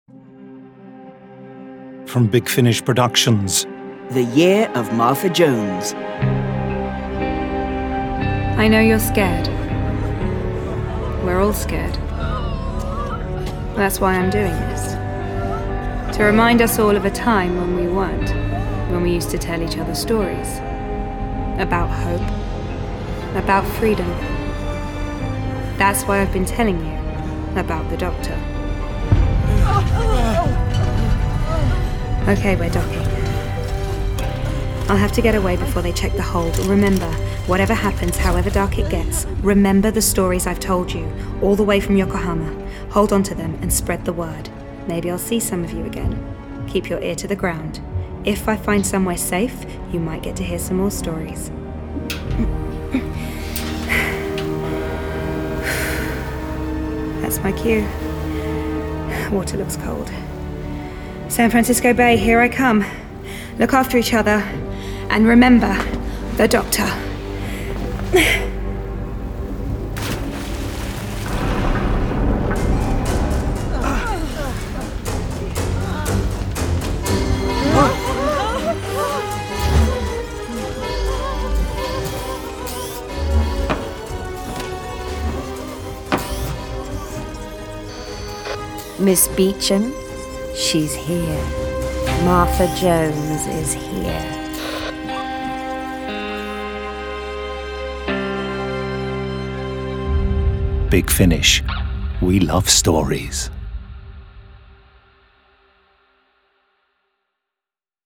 Award-winning, full-cast original audio dramas from the worlds of Doctor Who